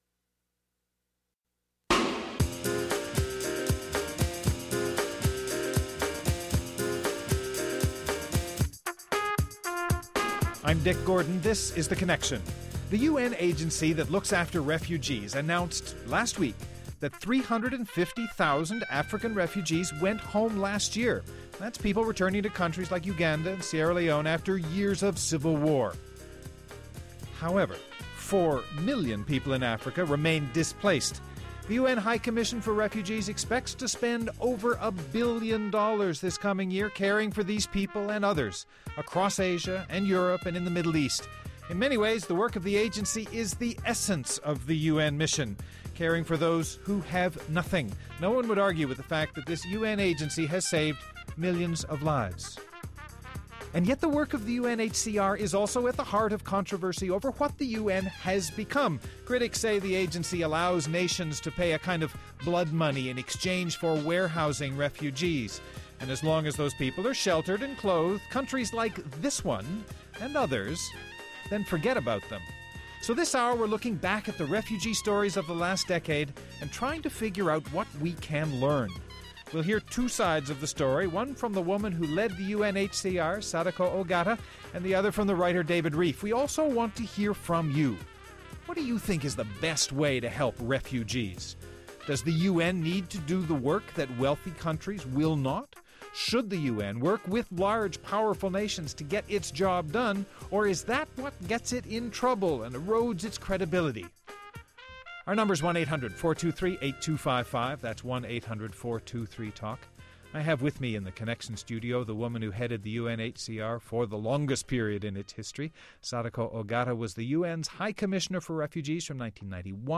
Now, Ogata is looking back on that turbulent time. We’ll talk with her, and test some of her conclusions.